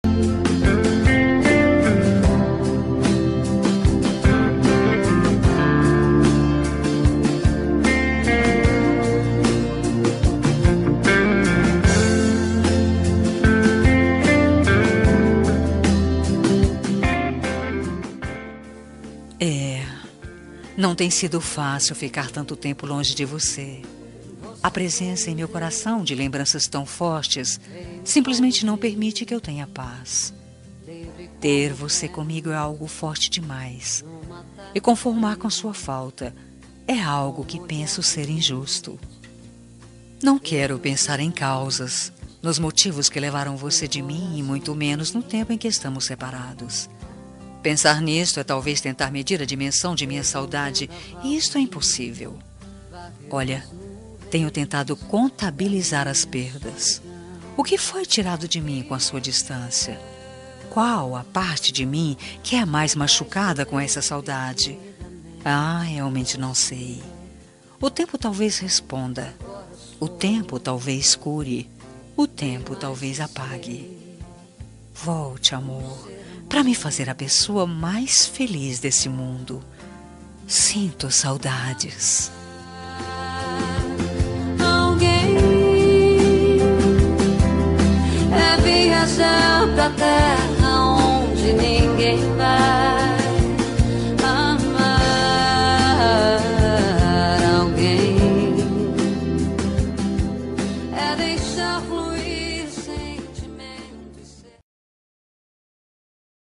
Telemensagem de Saudades – Voz Feminina – Cód: 432